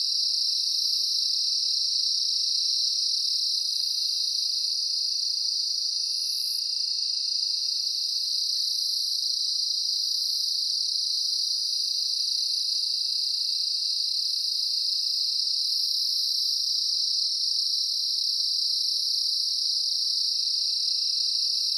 insectnight_13.ogg